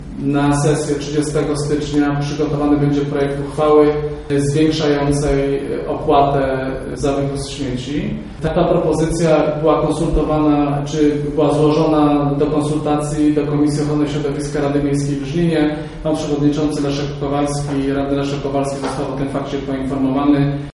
Teraz burmistrz Robert Luchowski proponuje by tę opłatę jeszcze bardziej podwyższyć. Informacje o tym, że chce by radni zaakceptowali nowe, wyższe stawki przekazał na dzisiejszej konferencji prasowej.